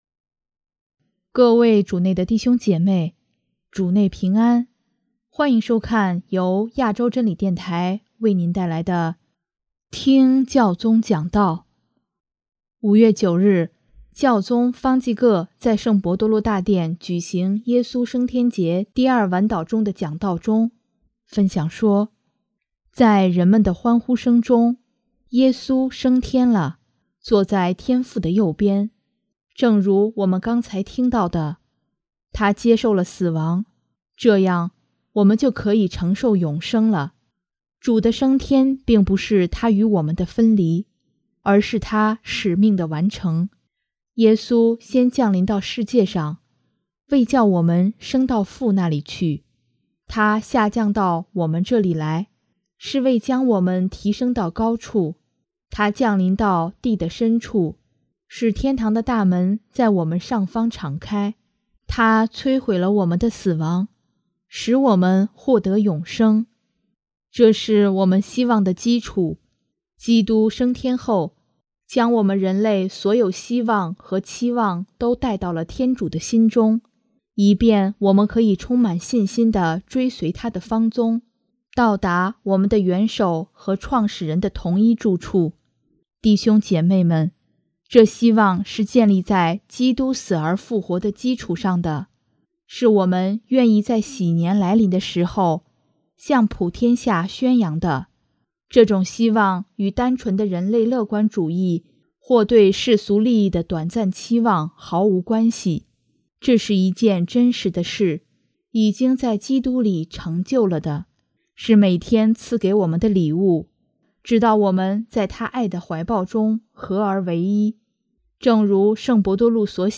5月9日，教宗方济各在圣伯多禄大殿举行耶稣升天节第二晚祷中的讲道中，分享说：